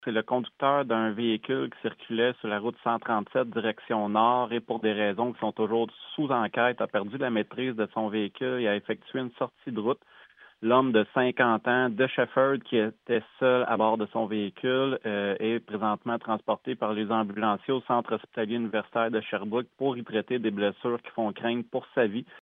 Nouvelles